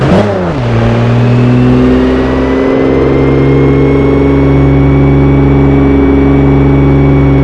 toyfj_shiftdown.wav